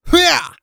XS发力02.wav
XS发力02.wav 0:00.00 0:00.63 XS发力02.wav WAV · 54 KB · 單聲道 (1ch) 下载文件 本站所有音效均采用 CC0 授权 ，可免费用于商业与个人项目，无需署名。
人声采集素材